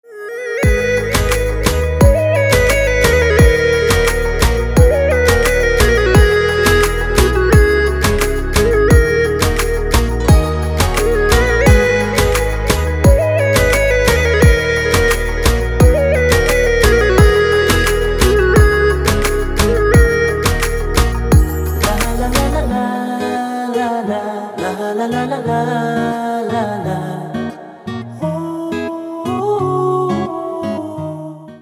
теги: красивый рингтон